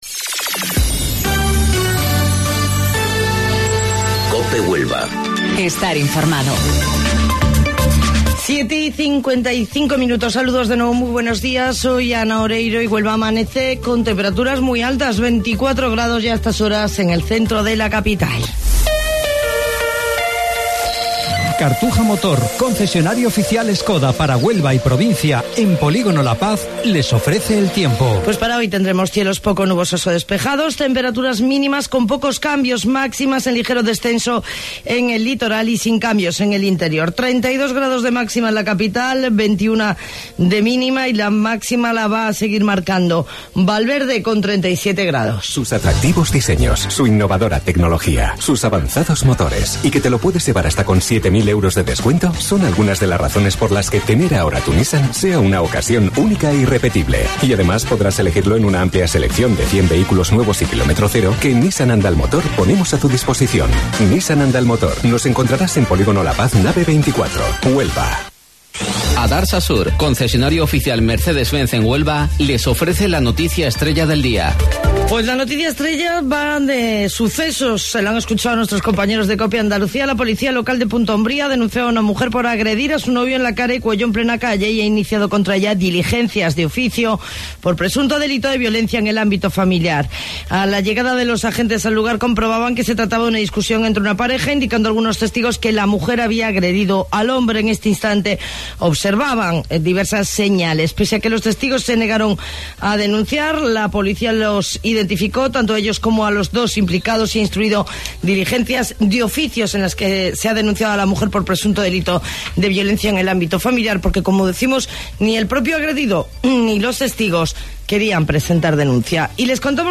AUDIO: Informativo Local 07:55 del 24 de Julio